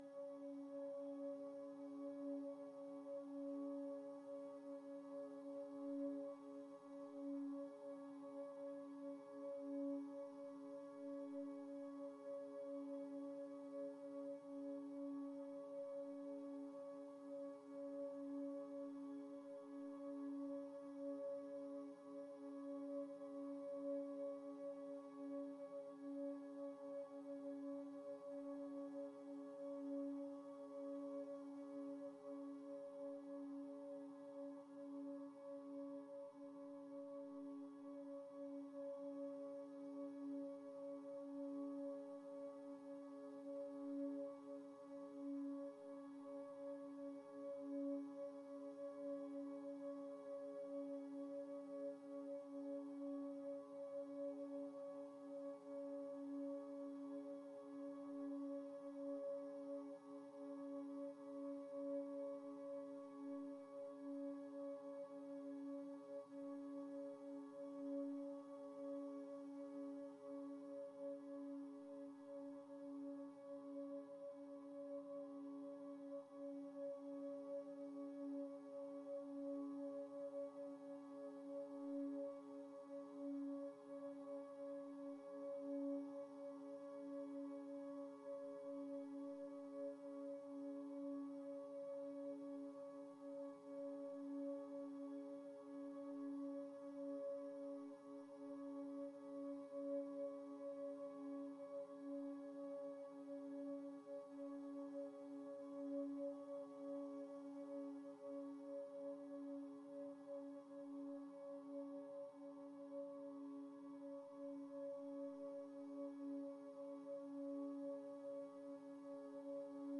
drone loop